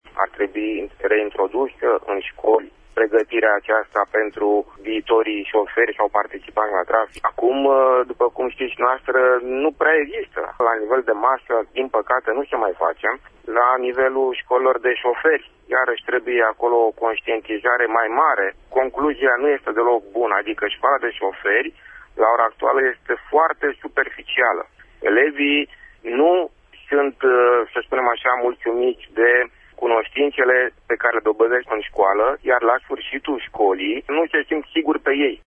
ce a început vineri la Braşov a declarat azi în emisiunea „Pulsul Zilei”: